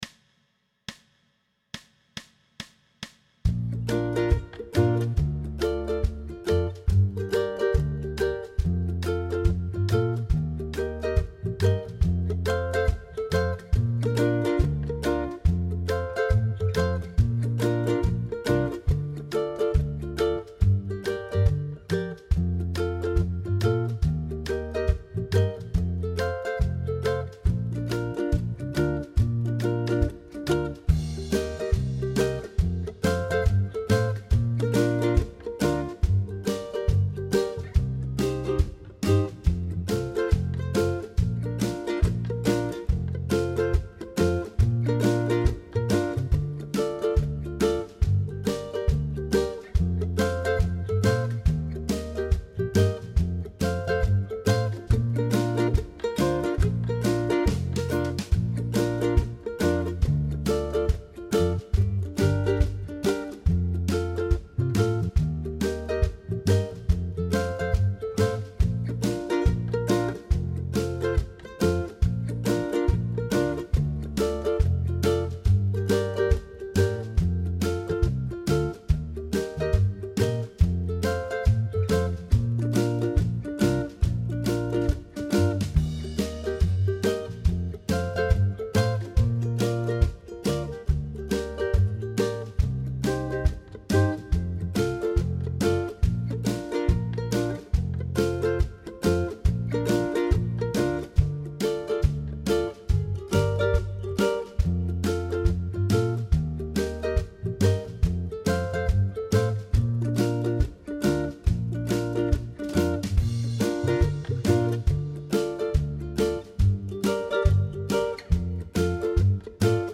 Pop style jam track with Ukulele Rhythm
Tempo: 140BPM
Key of Bb
ukulele-pop-140-bb.mp3